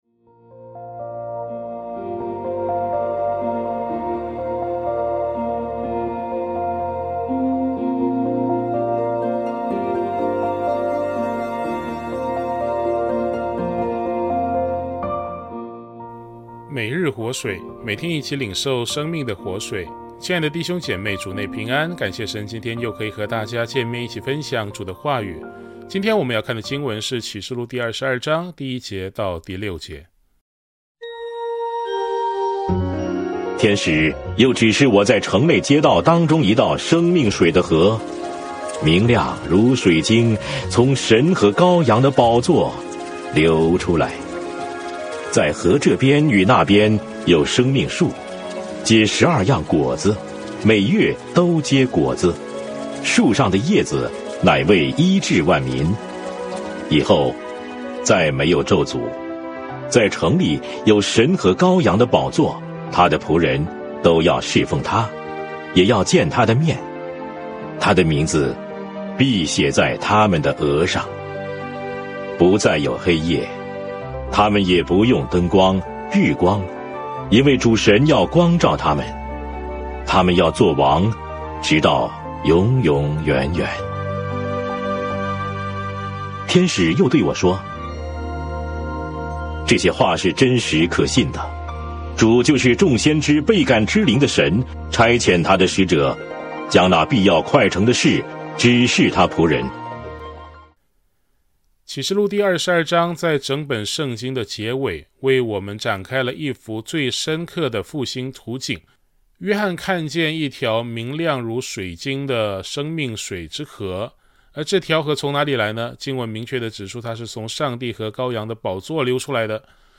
牧/者分享